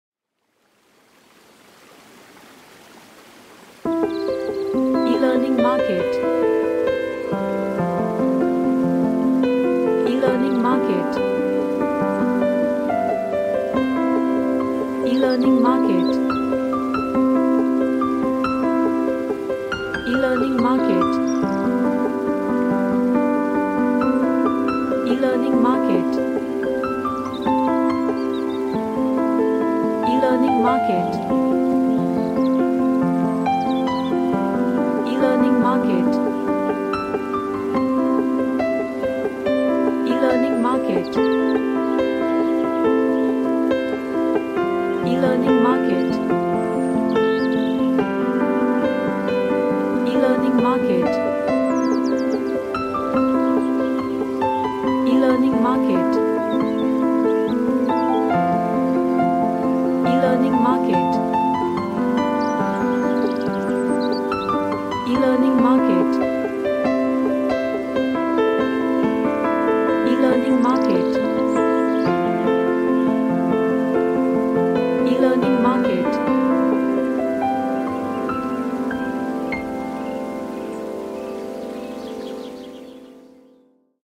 A cheerfull piano track with happy vibe
Happy / Cheerful